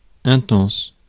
Ääntäminen
Ääntäminen France: IPA: [ɛ̃.tɑ̃s] Haettu sana löytyi näillä lähdekielillä: ranska Käännös Ääninäyte Adjektiivit 1. intense US 2. sharp GenAm US Suku: f .